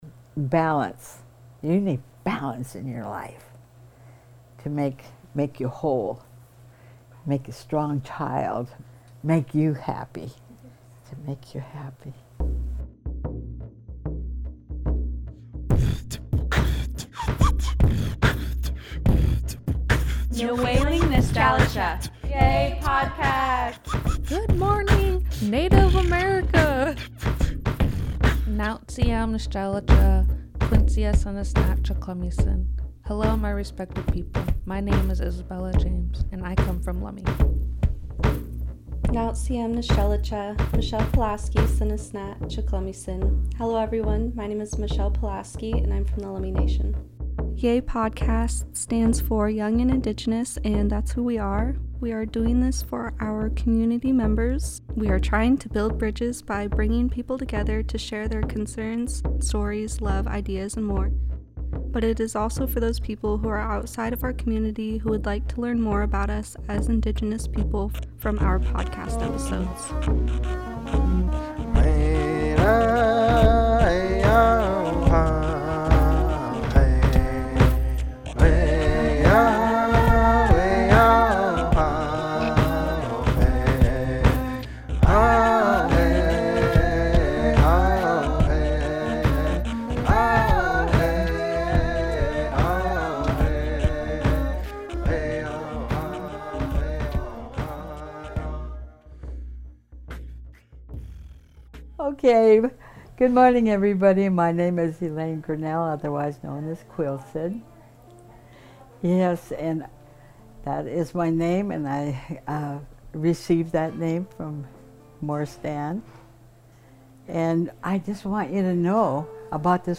Storytime